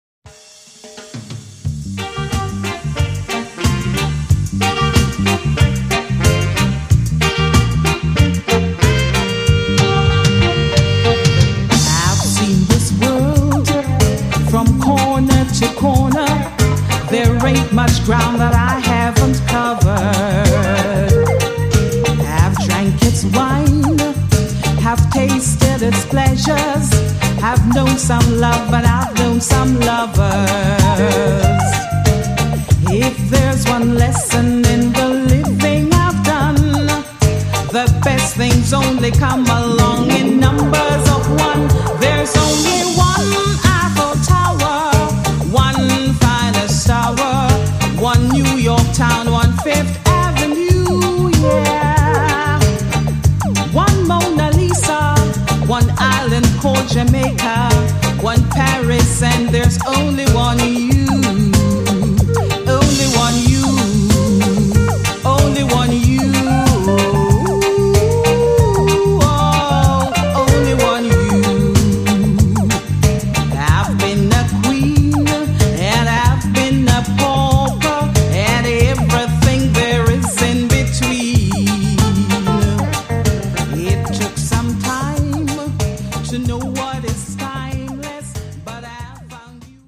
An obscure cover version, with a star-studded rhythm section